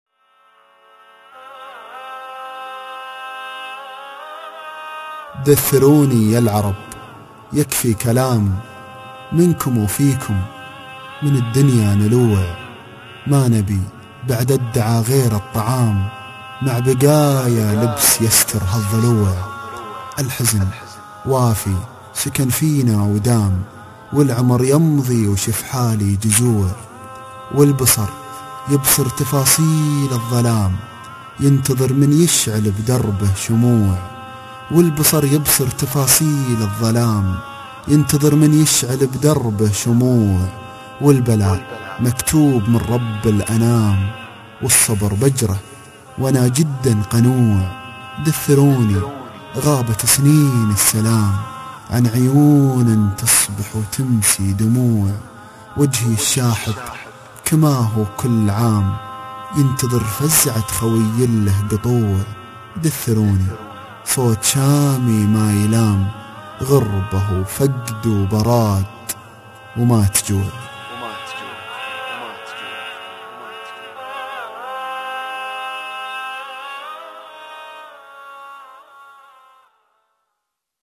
صوت شامي